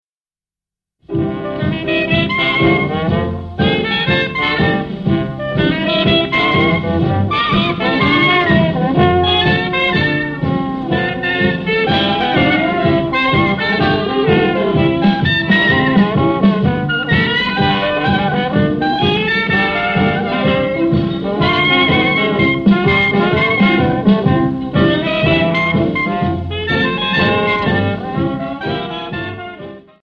Easy Listening Jazz View All